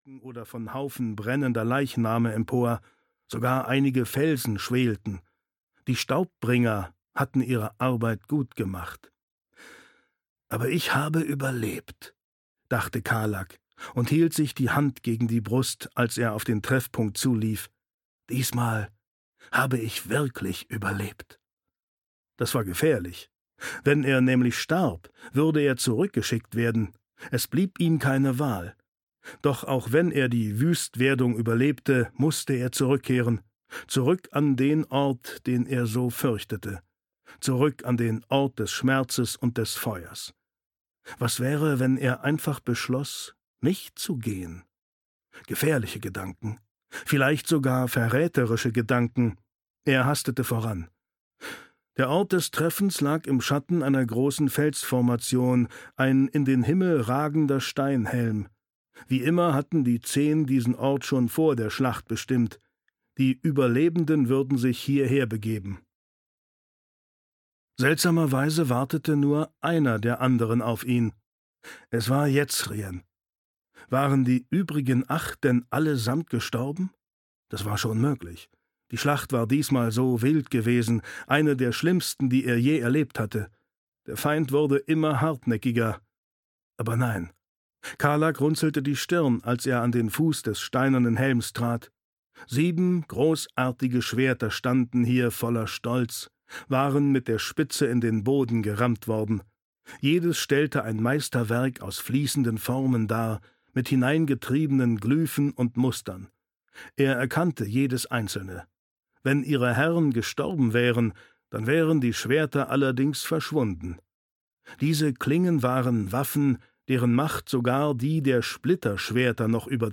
Der Weg der Könige (DE) audiokniha
Ukázka z knihy
• InterpretDetlef Bierstedt